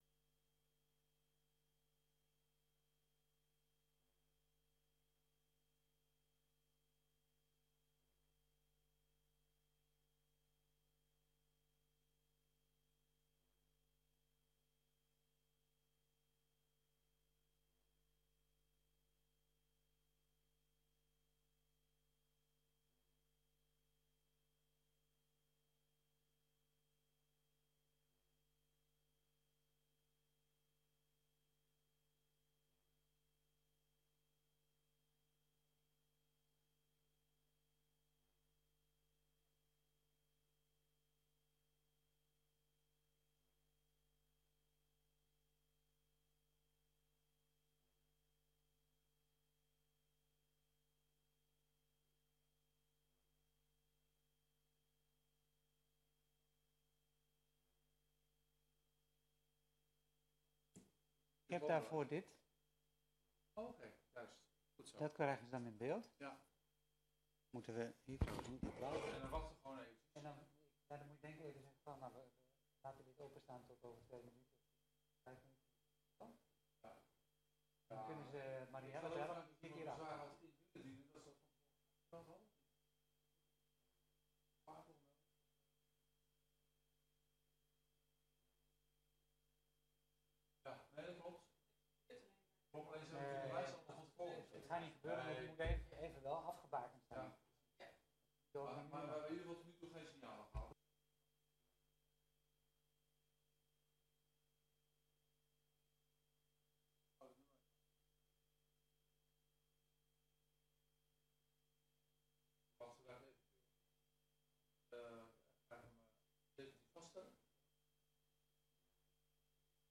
Openbare zitting van het centraal stembureau - Vaststelling kandidatenlijst Gemeenteraadsverkiezingen 2022 op vrijdag 4 februari 2022, om 10.00 uur.
Locatie: Raadzaal